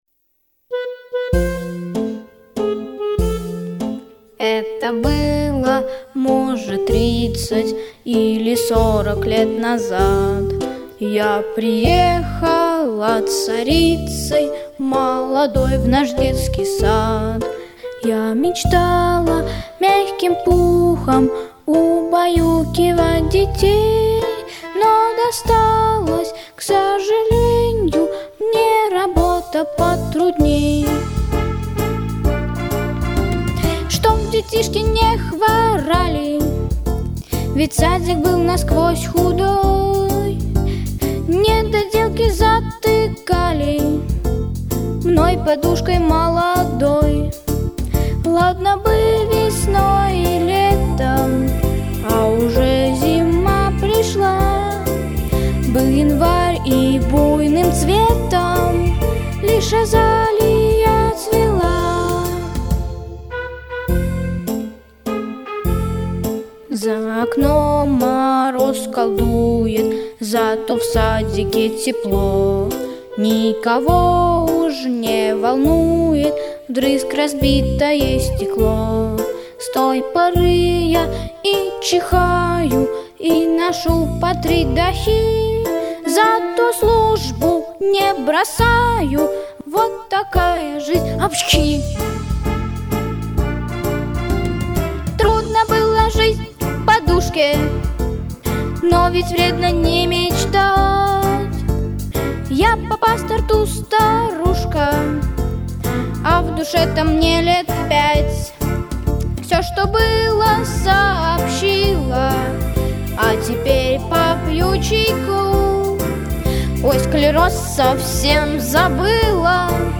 Записано в студии Easy Rider в ноябре–декабре 2025 года